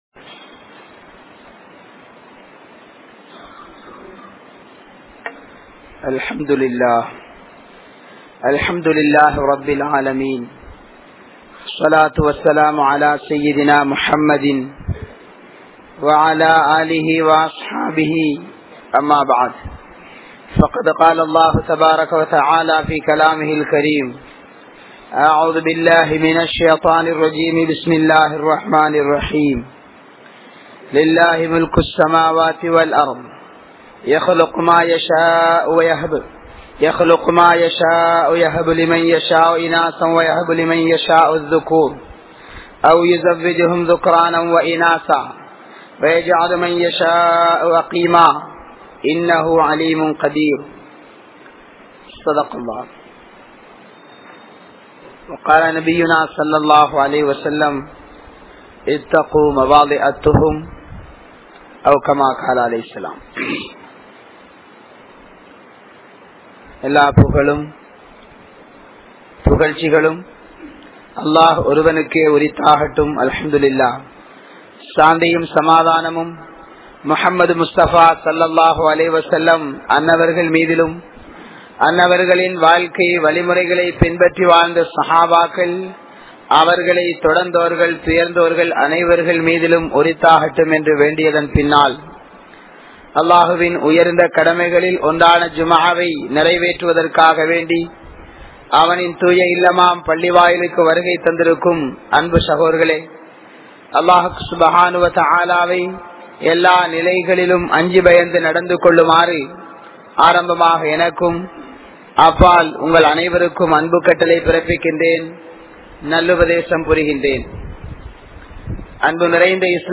Islam Anumathithulla Illara Uravu Murai (இஸ்லாம் அனுமதித்துள்ள இல்லற உறவு முறை) | Audio Bayans | All Ceylon Muslim Youth Community | Addalaichenai
Kanampittya Masjithun Noor Jumua Masjith